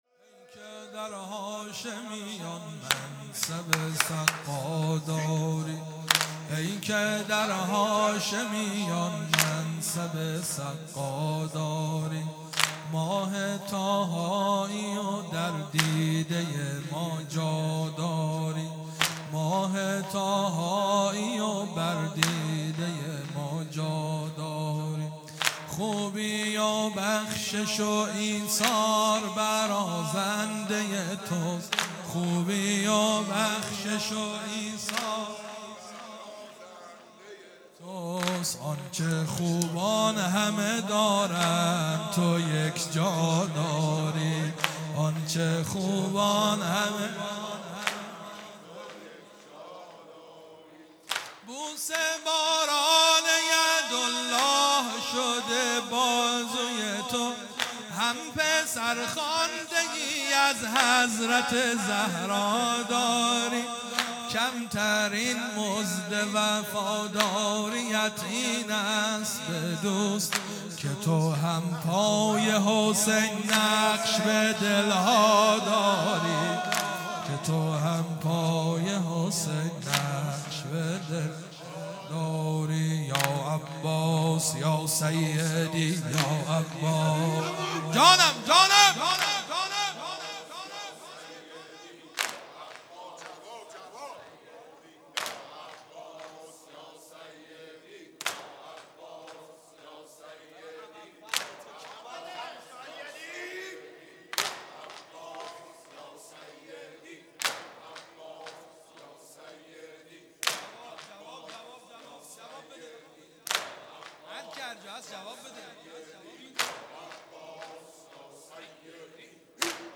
شهادت امام حسن مجتبی (ع) | ۱۷ آبان ۱۳۹۵